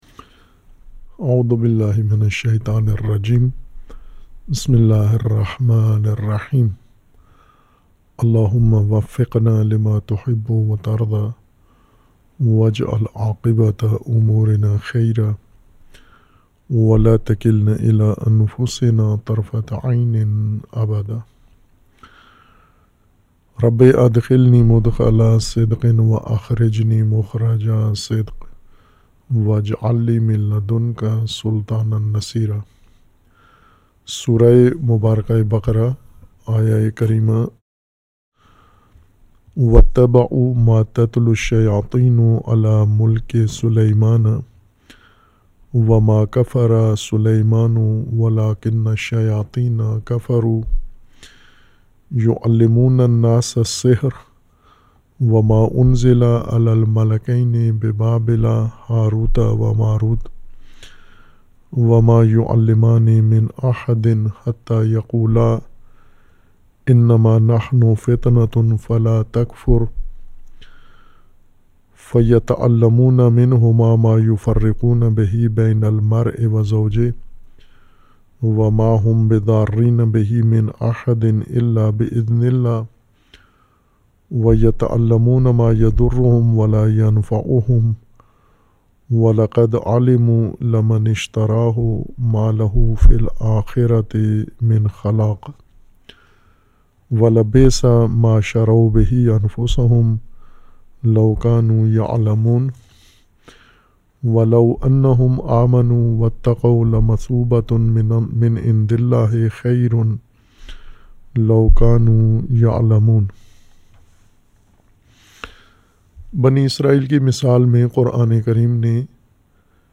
Dars e Tafseer e Quran
Offuq Al-Mobeen Studios , Jamia Urwat ul Wusqa Lahore.